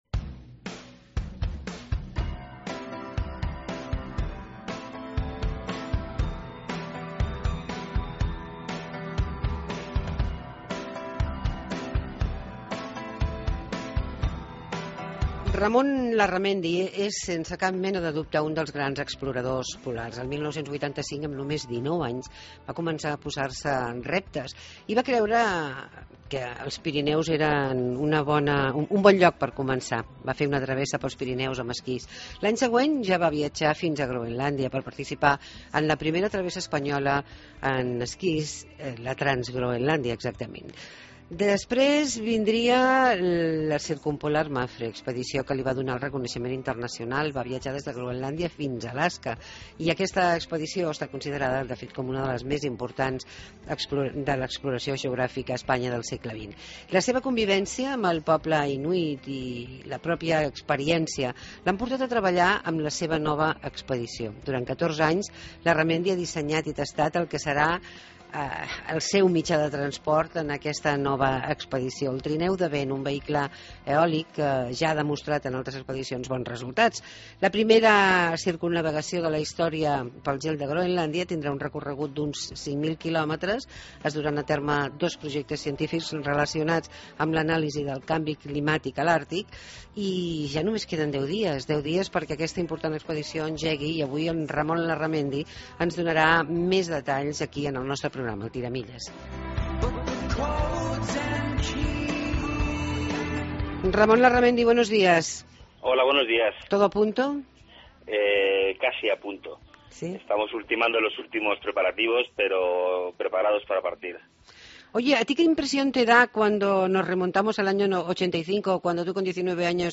AUDIO: Hablamos con Ramón Larramendi del trineo de viento y la expedición circunvalación de Groenlandia, donde lo utilizará.